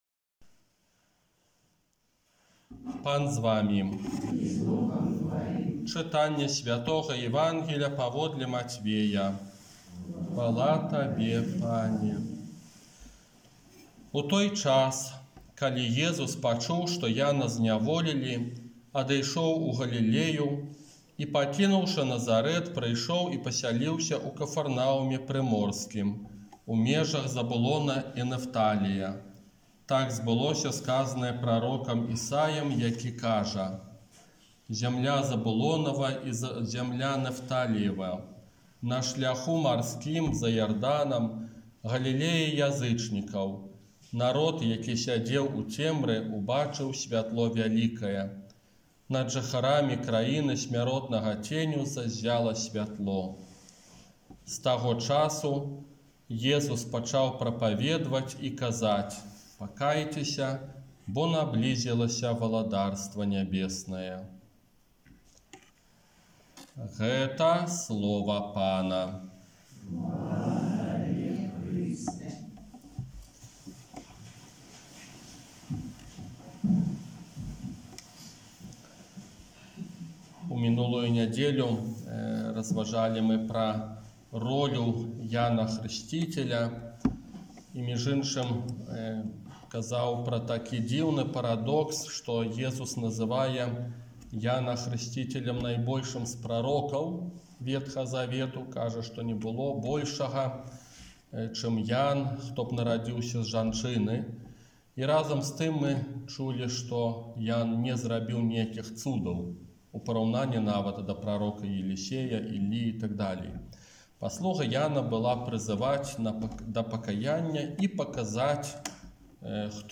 ОРША - ПАРАФІЯ СВЯТОГА ЯЗЭПА
Казанне на трэццюю звычайную нядзелю